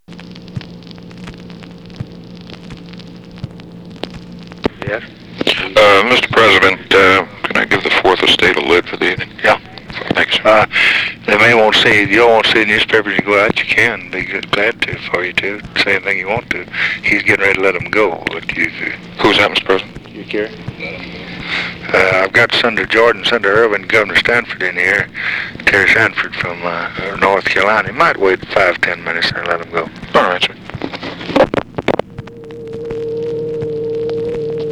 Conversation with MAC KILDUFF and OFFICE CONVERSATION, September 14, 1964
Secret White House Tapes